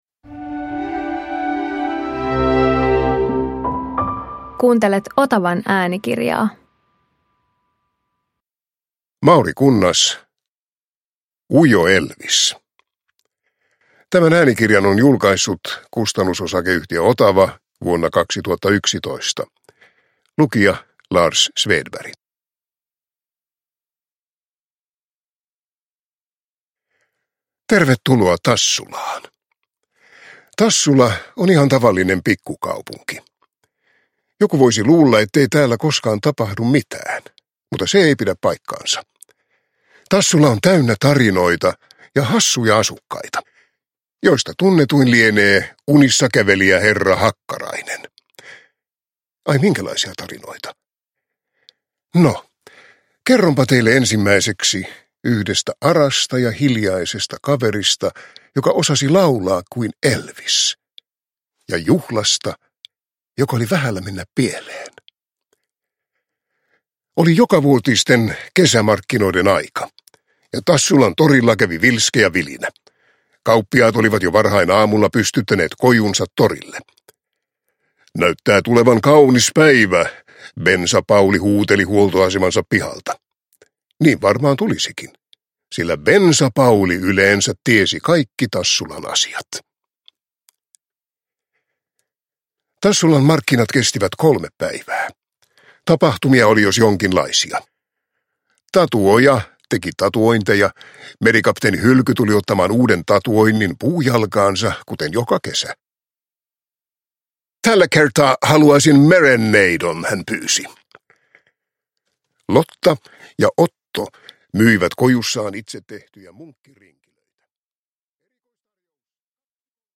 Ujo Elvis – Ljudbok